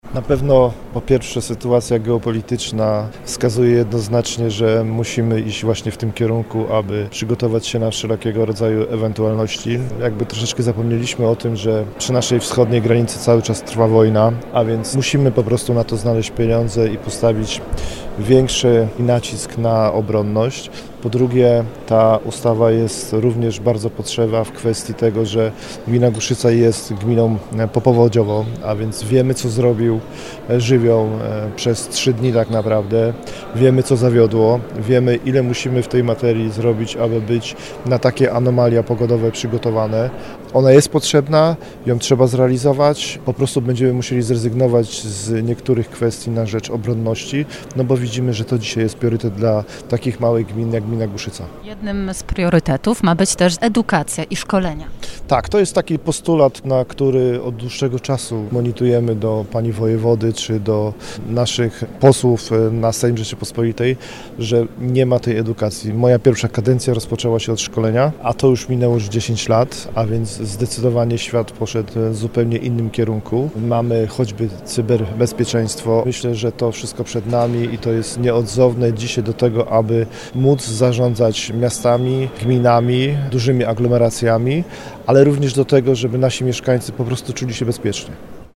Jak nowe przepisy oceniają samorządowcy? -Musimy iść w tym kierunku – komentuje Roman Głód, Burmistrz Głuszycy.